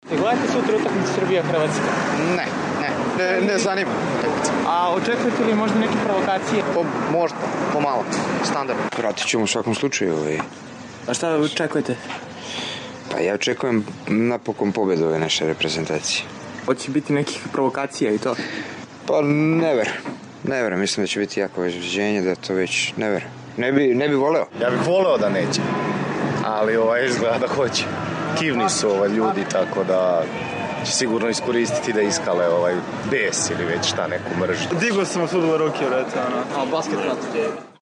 Beograđani sa kojima smo razgovarali, pak, različito gledaju na predstojeći meč:
Građani o utakmici sa Hrvatskom